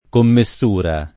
vai all'elenco alfabetico delle voci ingrandisci il carattere 100% rimpicciolisci il carattere stampa invia tramite posta elettronica codividi su Facebook commessura [ komme SS2 ra ] (antiq. commissura [ kommi SS2 ra ]) s. f.